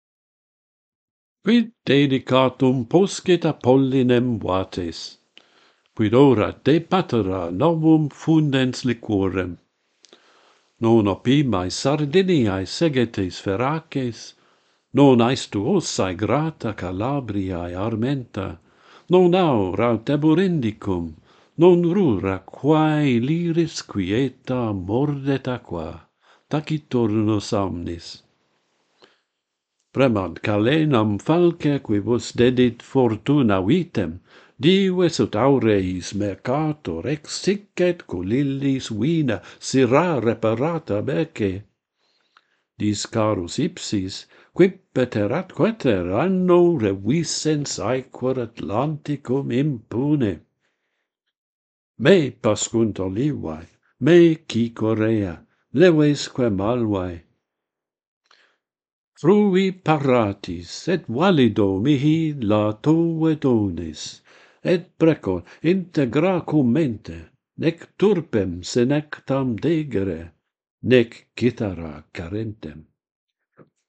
A Prayer to the poetry-God - Pantheon Poets | Latin Poetry Recited and Translated
The metre is Alcaics.